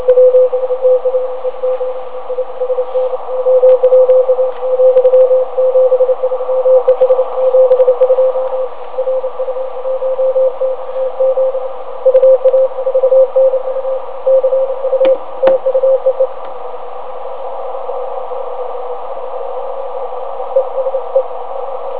Нахожусь в центральной части Украины, приём на НЕнастроенную НЕантенну.